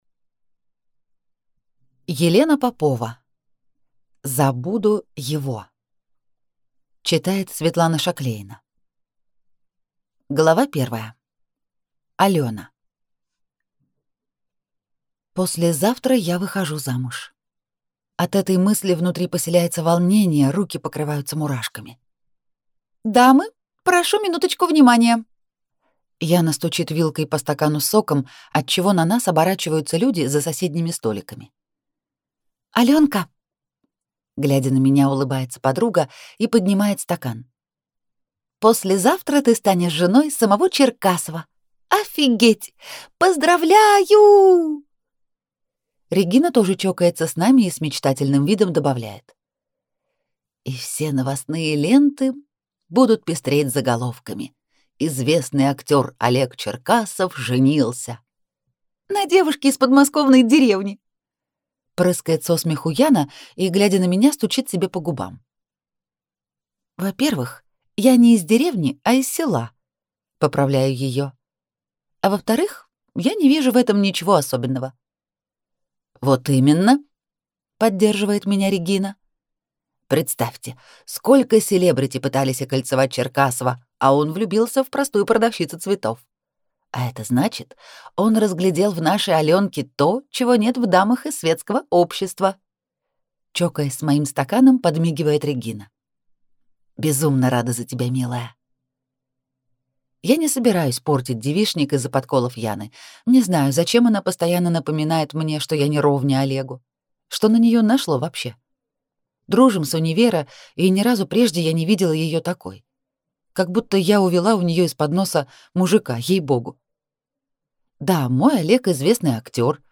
Аудиокнига (За)буду его | Библиотека аудиокниг